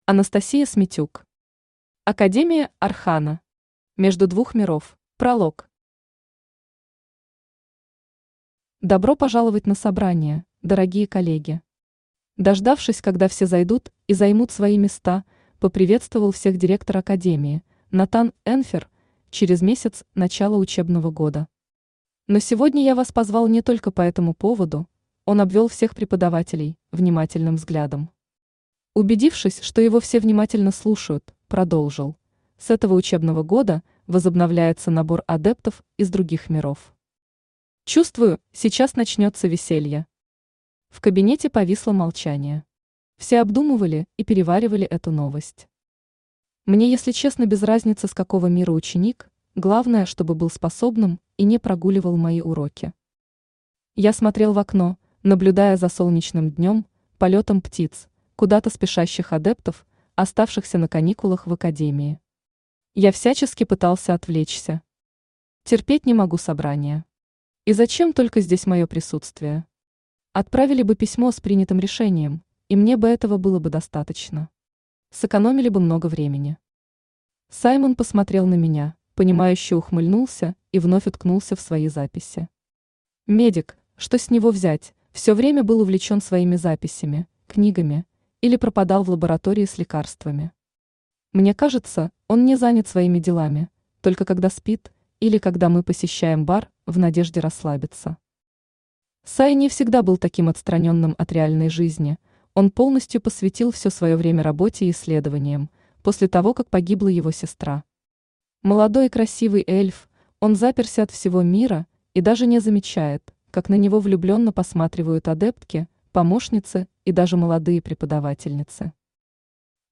Между двух миров Автор Анастасия Смитюк Читает аудиокнигу Авточтец ЛитРес.